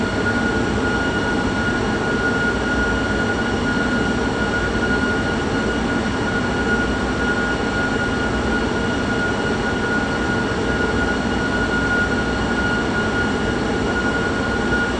A320-family/Sounds/SASA/CFM56B/cockpit/cfm-lowspool.wav at c202b1e67eab690ba06036c7b7050fa7900a59cf